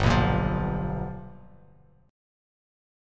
G7#9 chord